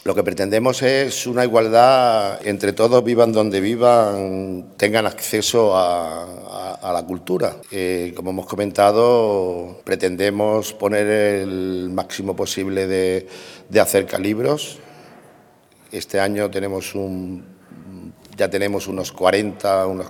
El diputado de Juventud y Educación, Ignacio Trujillo, ha presentado en rueda de prensa el programa ‘Acercalibros’, una iniciativa de fomento de la lectura que en esta primera edición ya se va a desplegar en más de 40 barriadas rurales, según ha explicado el diputado.